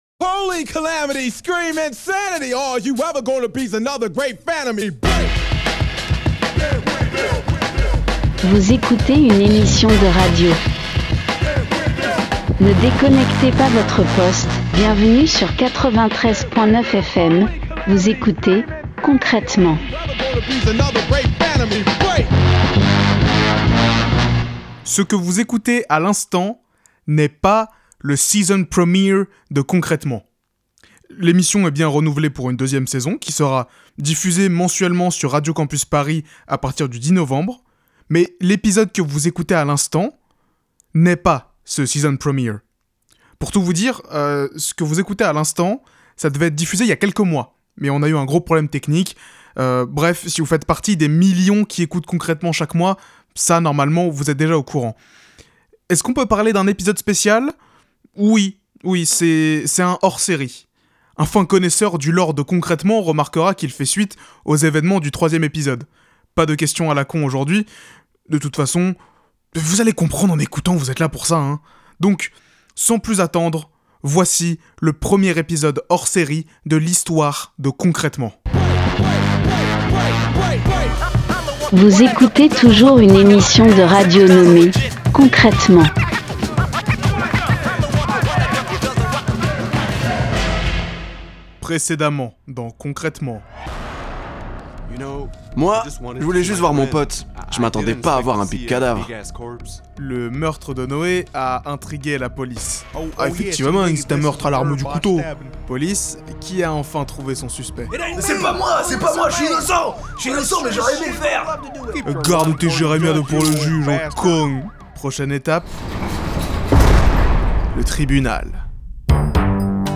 Création sonore